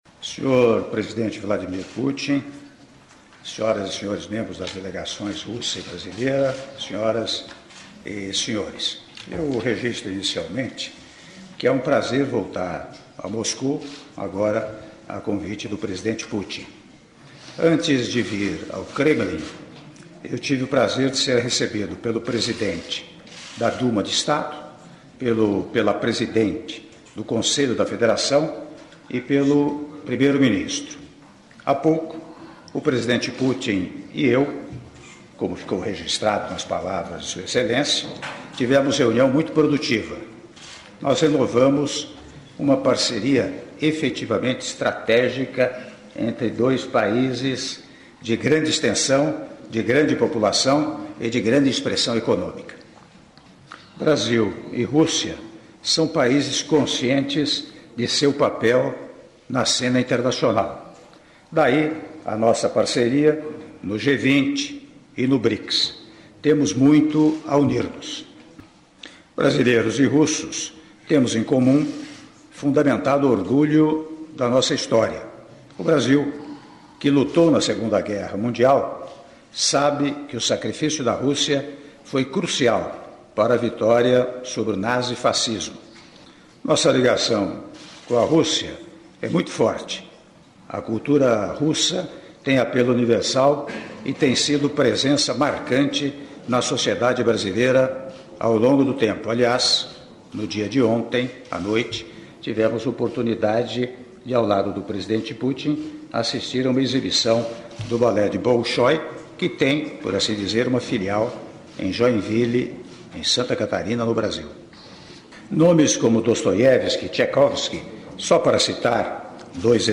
Áudio do discurso do Presidente da República, Michel Temer, durante cerimônia de Assinatura de Atos - Moscou/Rússia- (05min43s)